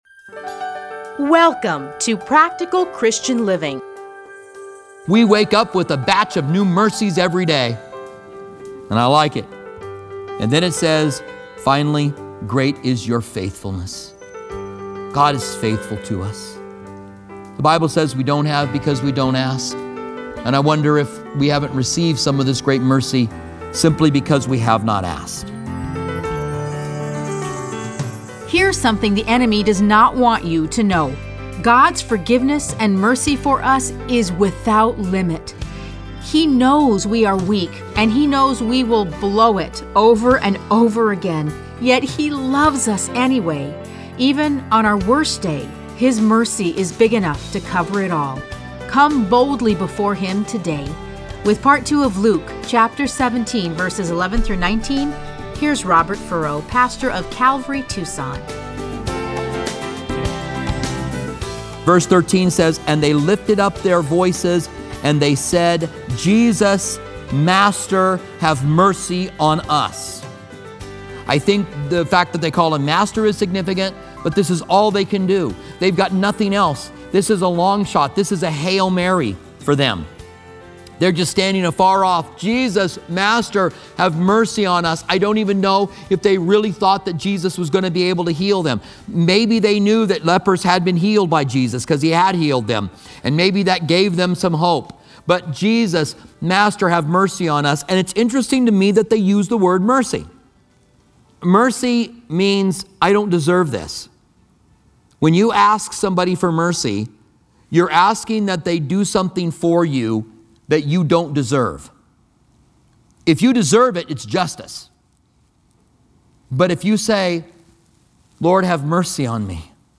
Listen to a teaching from Luke 17:11-19.